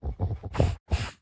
Minecraft Version Minecraft Version snapshot Latest Release | Latest Snapshot snapshot / assets / minecraft / sounds / mob / sniffer / searching2.ogg Compare With Compare With Latest Release | Latest Snapshot